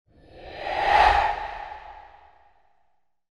supernatural-breath-sound